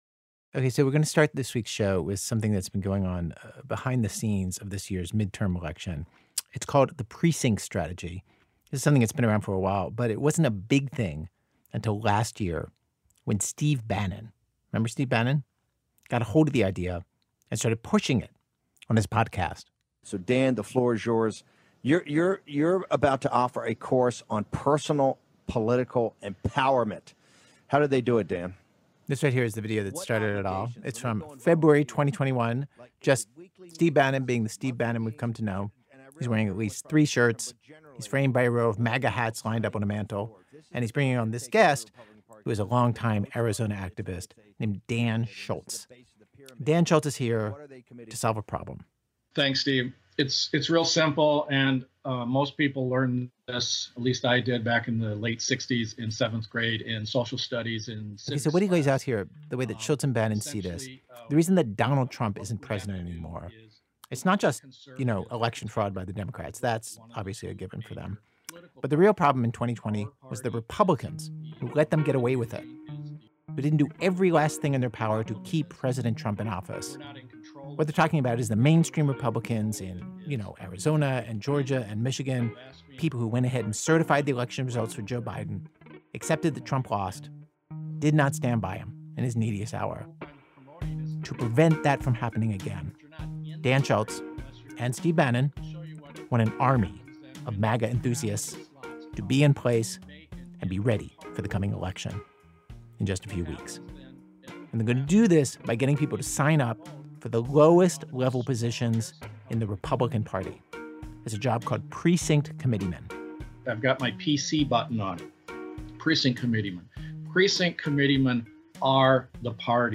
Note: The internet version of this episode contains un-beeped curse words.
Host Ira Glass looks into what’s going on behind the scenes of this year’s midterm election.